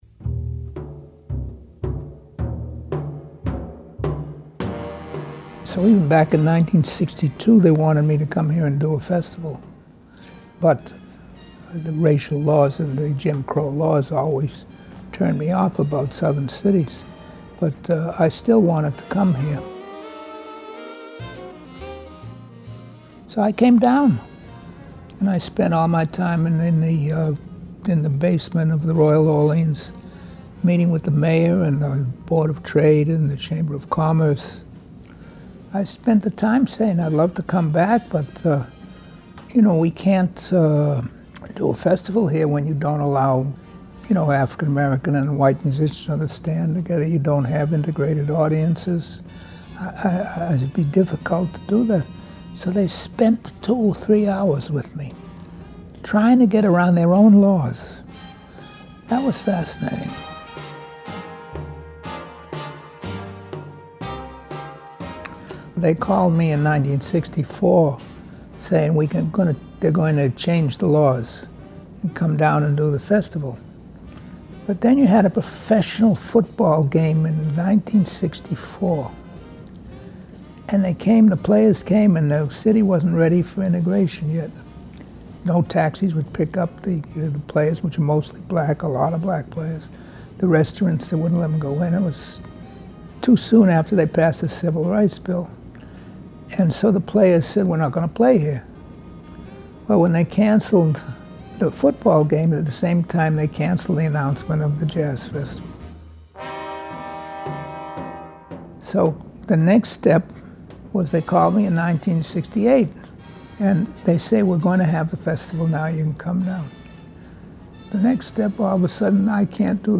Click here to listen to hardships George Wein had to undergo in order to get the New Orleans Jazz & Heritage Festival started. Music by Duke Ellington and Mahalia Jackson, performers at the first festival.